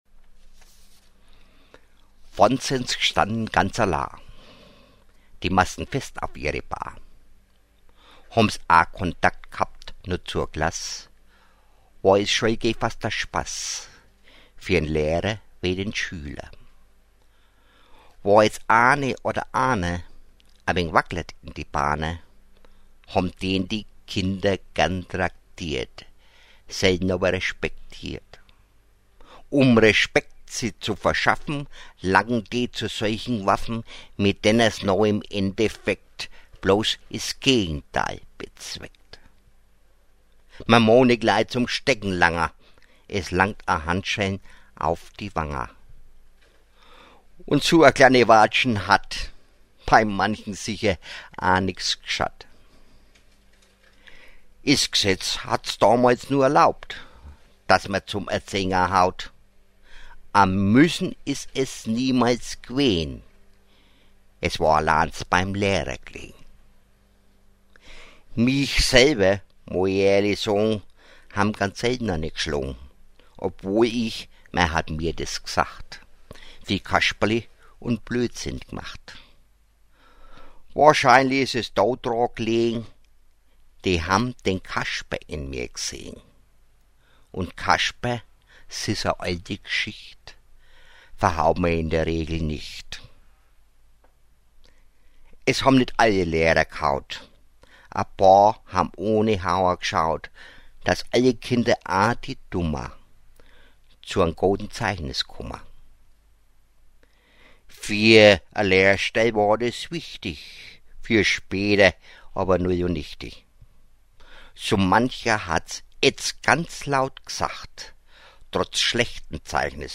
Meine Mundart-Gedichte